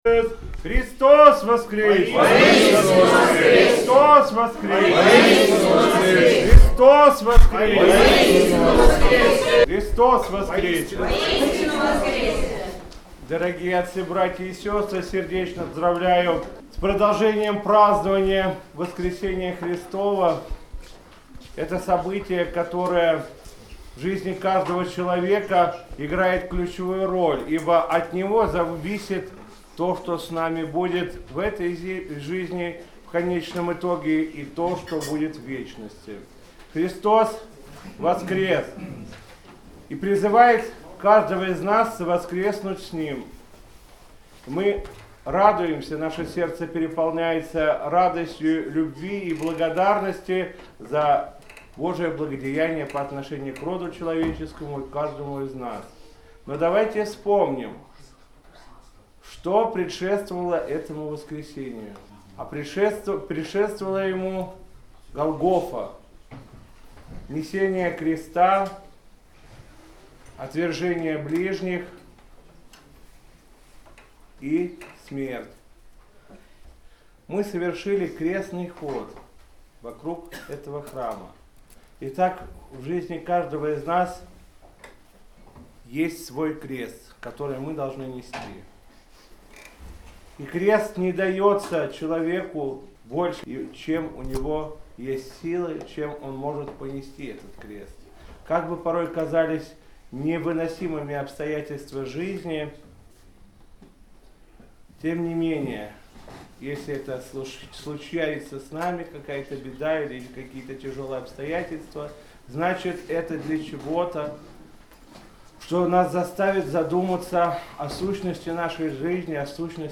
Слово после Литургии в среду Светлой седмицы